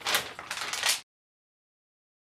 SFX展开一张纸的声音音效下载
SFX音效